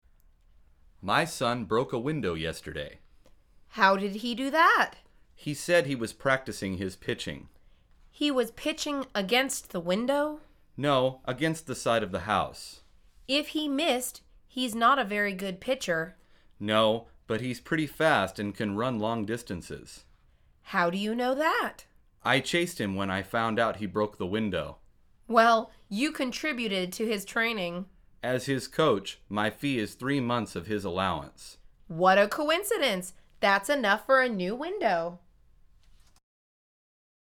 مجموعه مکالمات ساده و آسان انگلیسی – درس شماره سوم از فصل مسکن: پنجره شکسته